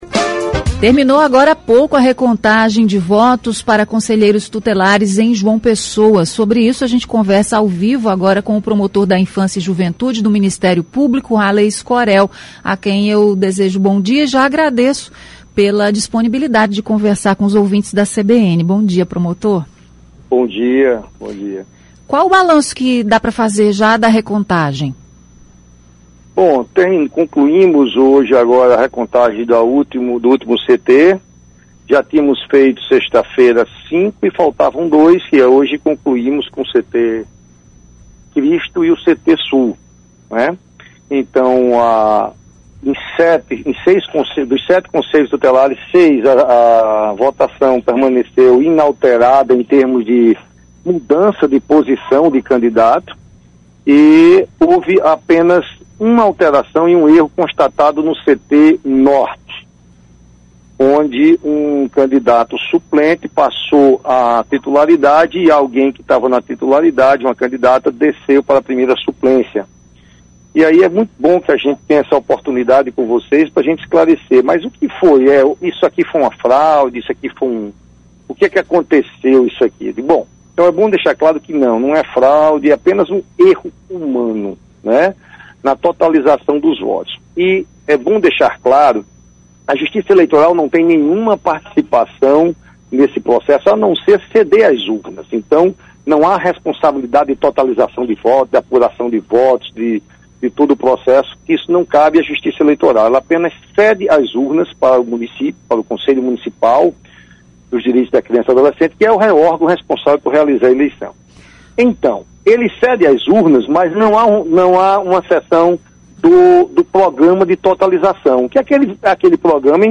Entrevista: resultados da recontagem para conselheiros tutelares
O promotor da Infância e Juventude do Ministério Público da Paraíba (MPPB), Alley Escorel, falou nesta segunda-feira (23) sobre os resultados da recontagem para conselheiros tutelares em João Pessoa.